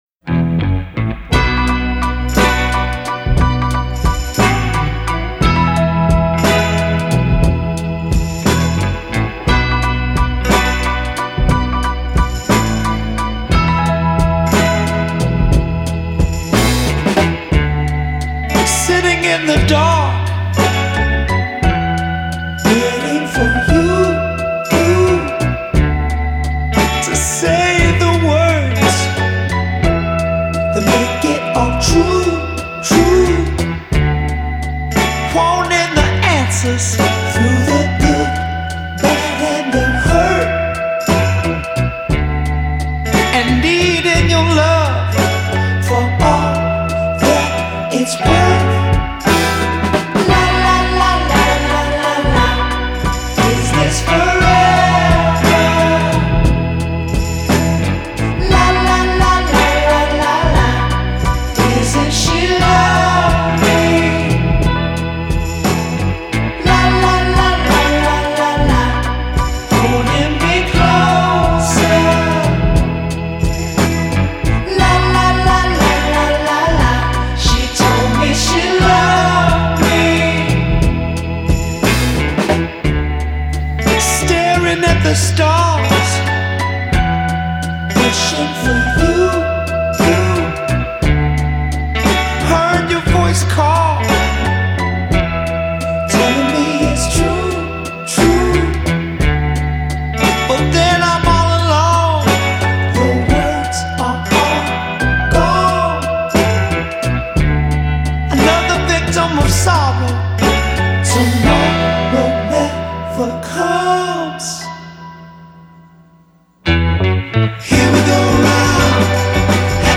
Style: Funk, Soul, Psychedelic